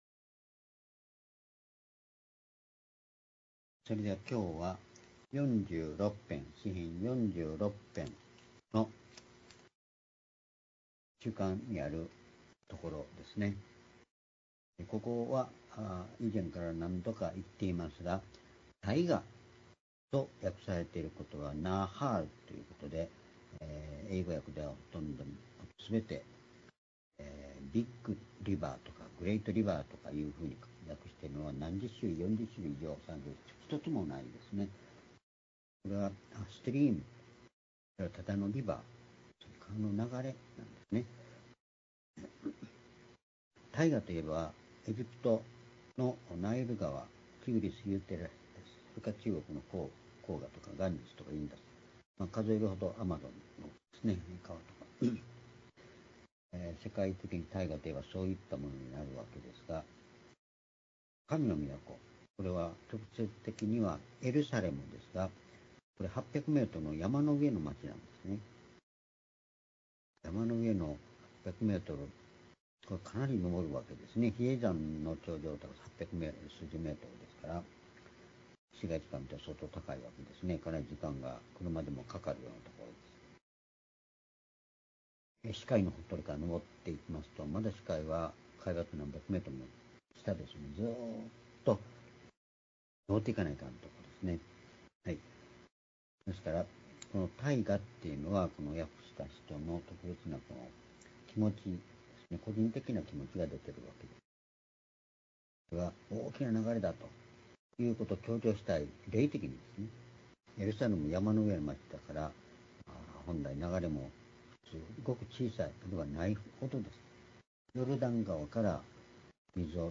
（主日・夕拝）礼拝日時 2025年1月21日（夕拝） 聖書講話箇所 「混乱と闇のただ中における命の水の流れ」 詩編４６編５節～８節 ※視聴できない場合は をクリックしてください。